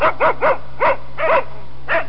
Bark Sound Effect
Download a high-quality bark sound effect.
bark.mp3